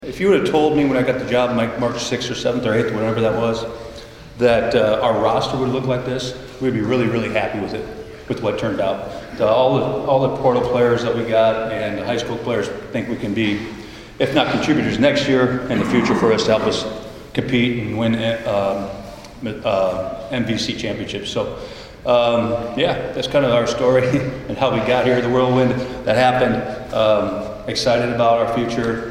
talks basketball to Hopkinsville Kiwanis Club